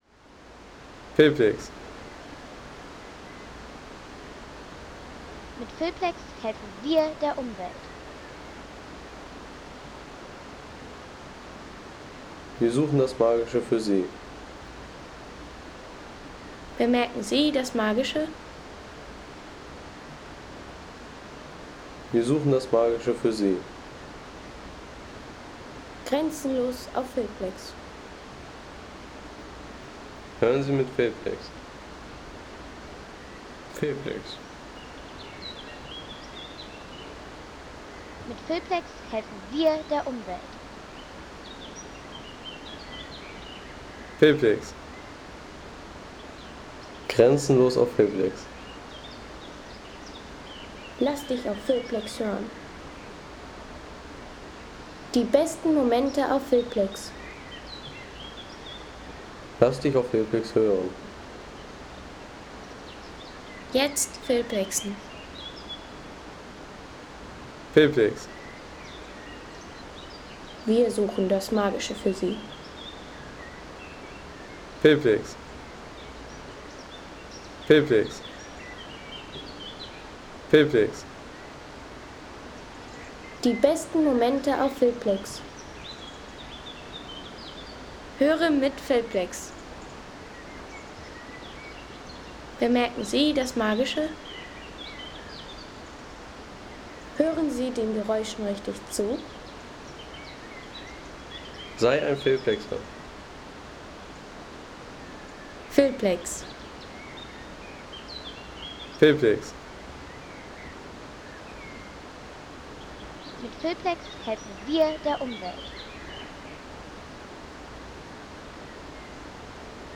Morgenzauber am Rockenburger Urwaldpfad – Lauschen Sie dem Erwachen ... 15,50 € Inkl. 19% MwSt.